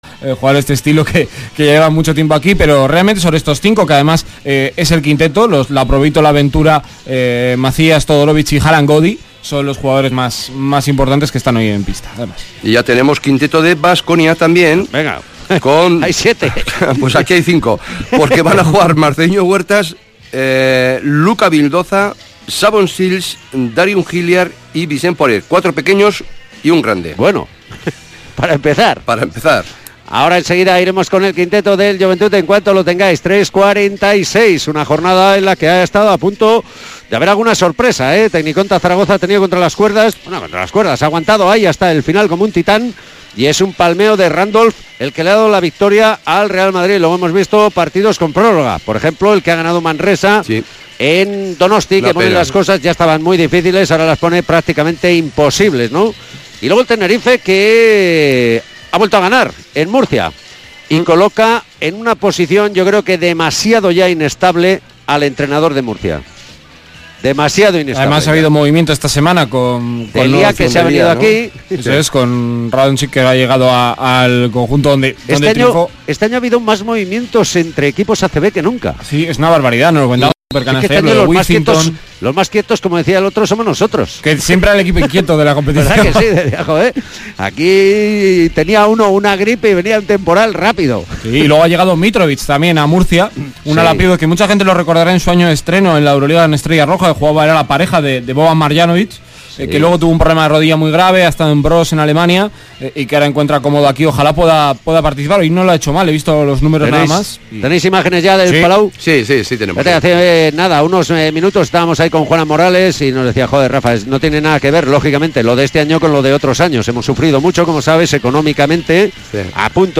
Divinas Seguros Joventut-Kirolbet Baskonia jornada 16 ACB 2018-19 retransmisión completa Radio Vitoria